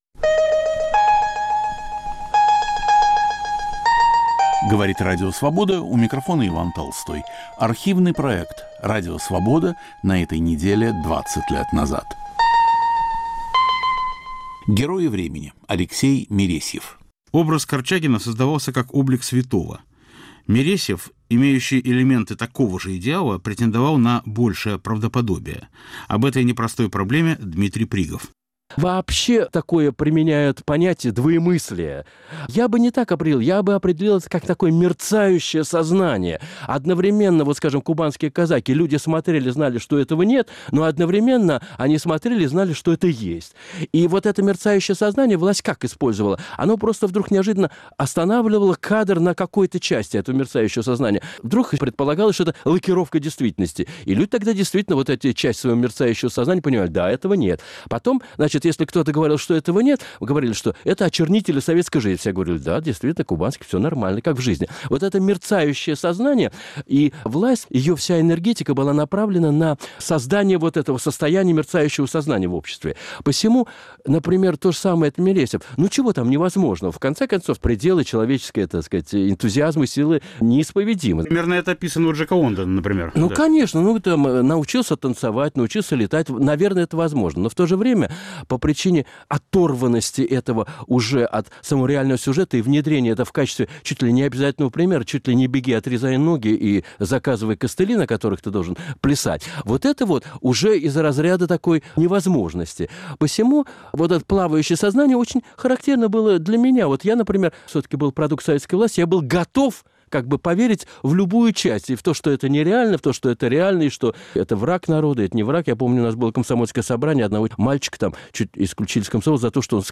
Автор и ведущий Петр Вайль.